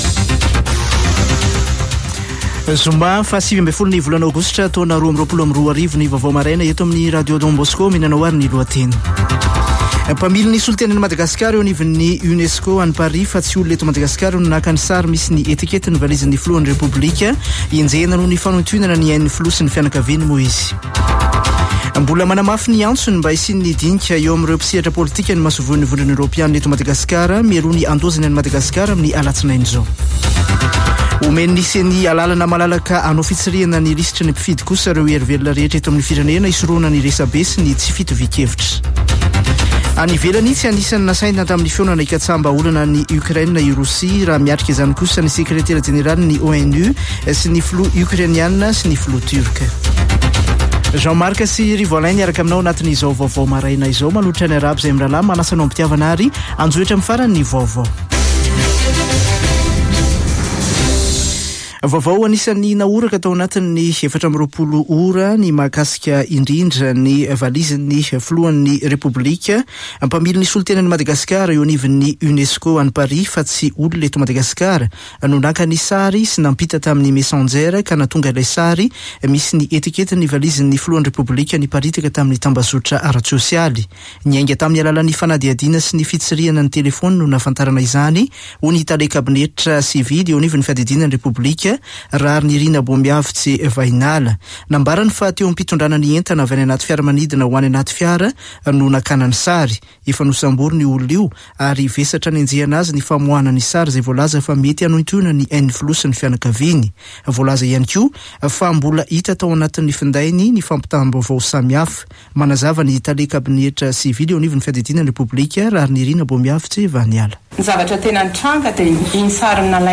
[Vaovao maraina] Zoma 19 aogositra 2022